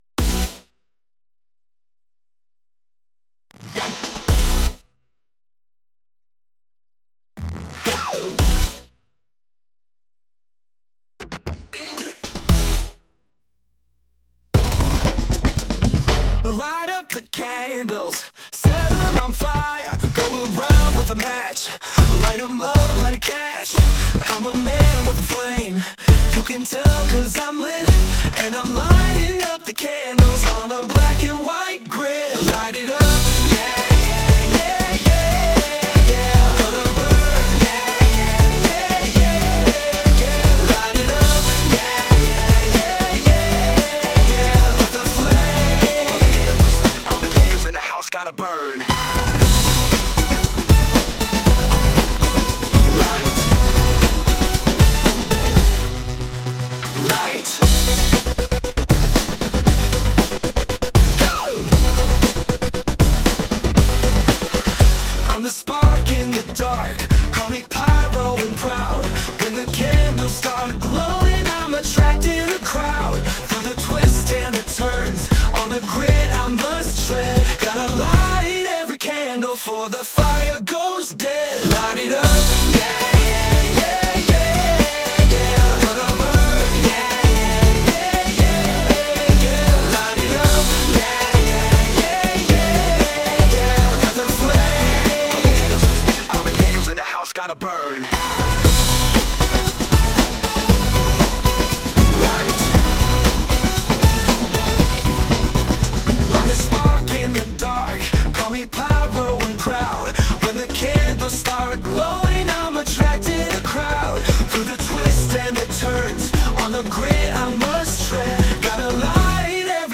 Burning version
(The start takes a while!)
Sung by Suno